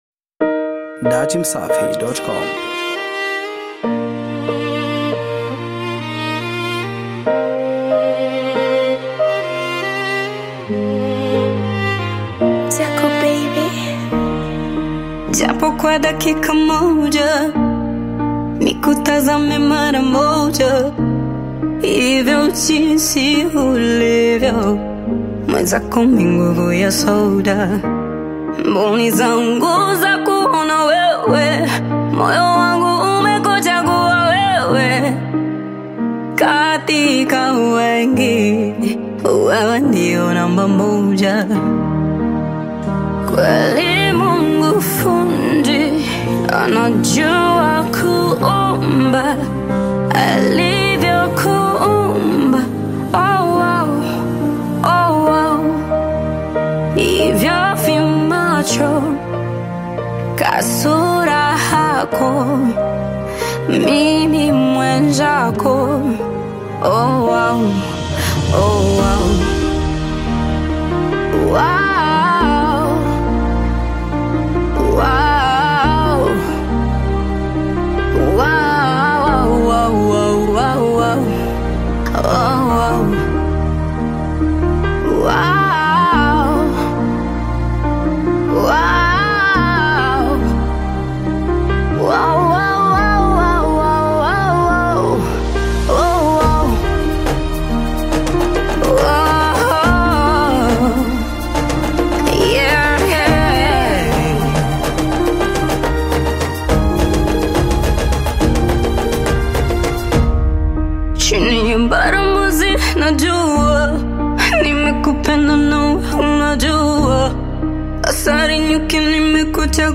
” a song packed with vibrant energy and feel-good vibes.